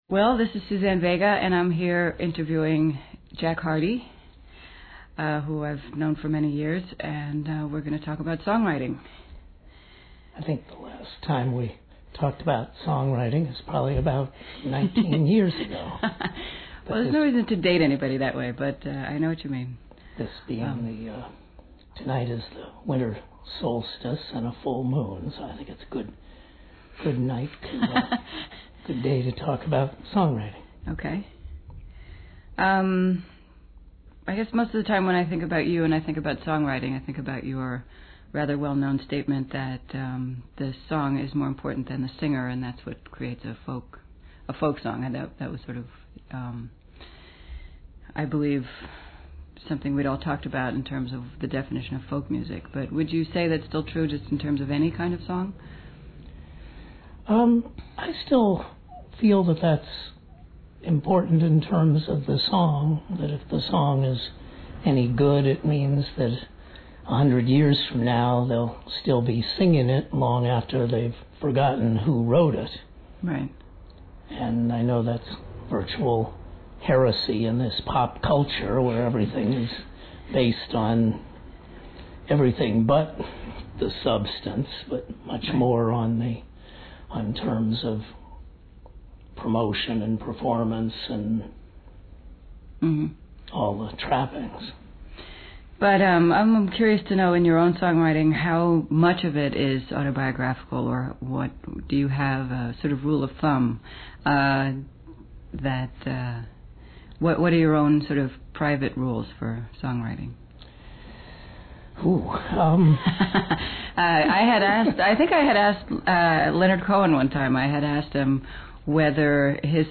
Interview by Suzanne Vega